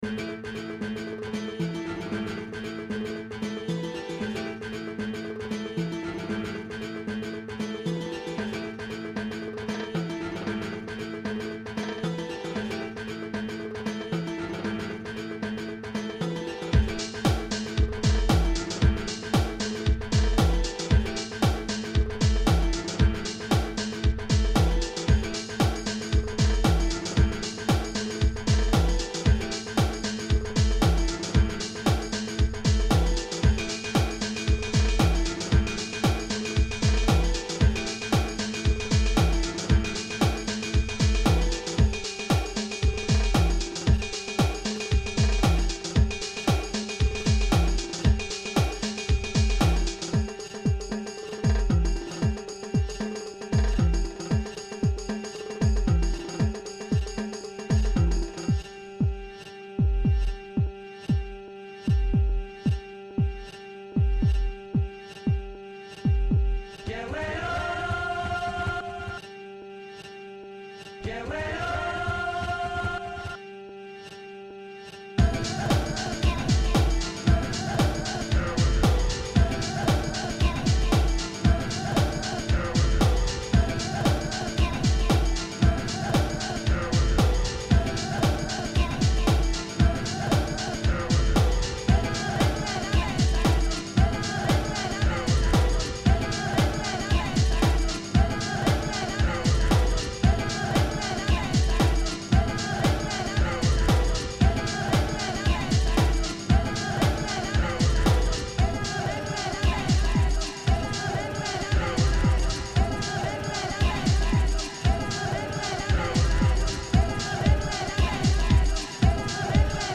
[ Original Format : Impulse Tracker
going well, the vocals don't fit in properly anymore.
the cool vocal samples.